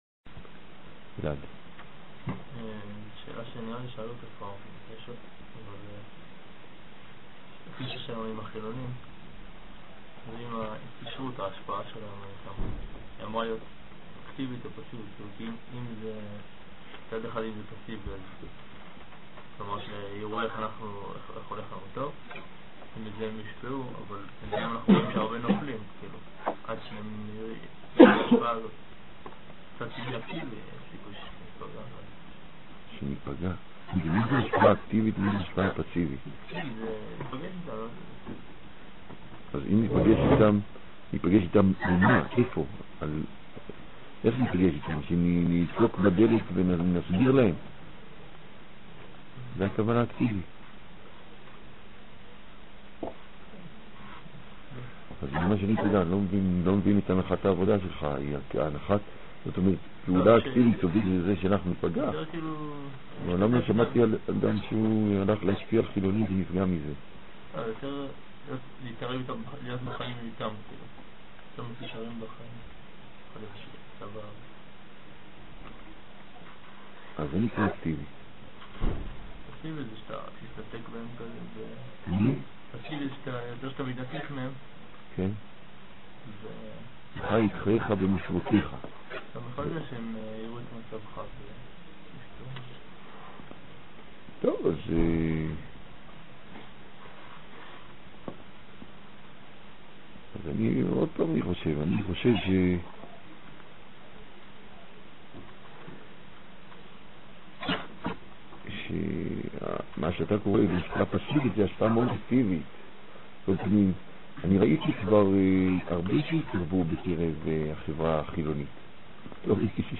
האם השפעתנו על החילונים צריכה להיות אקטיבית או פסיבית? מתוך שו"ת. ניתן לשלוח שאלות בדוא"ל לרב